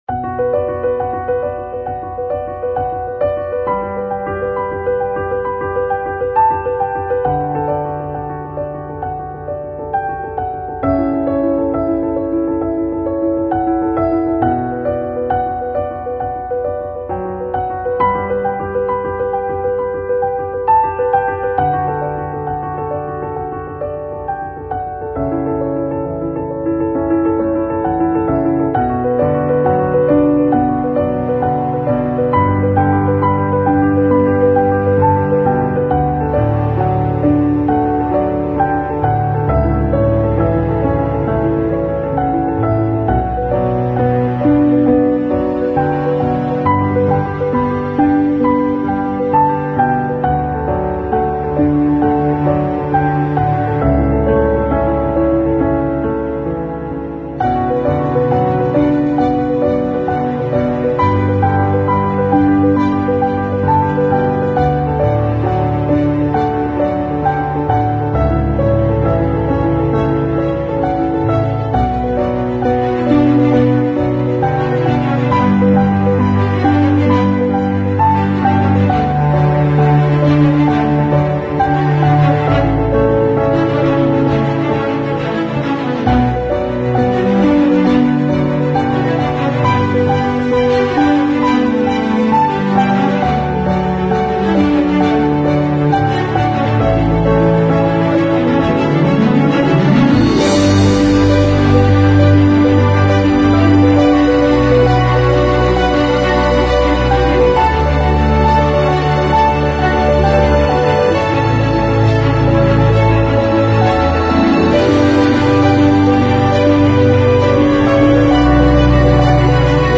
16-Bit Stereo 16位立体声, 44.1 kHz